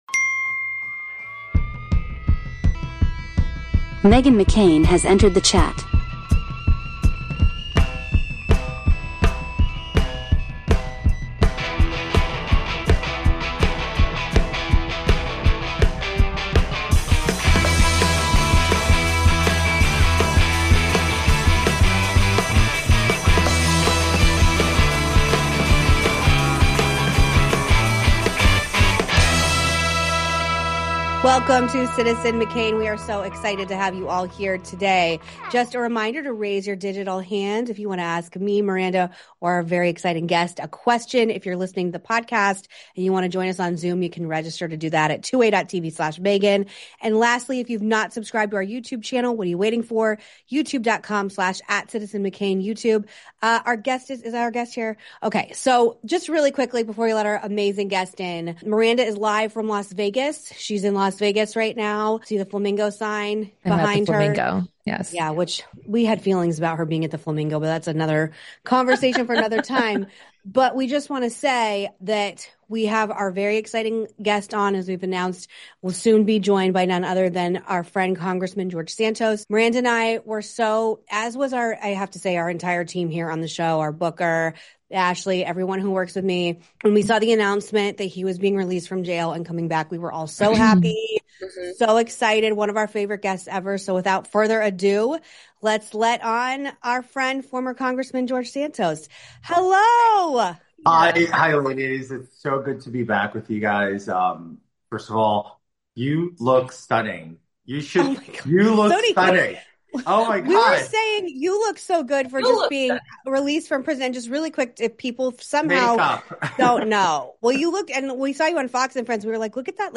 Society & Culture, News Commentary, News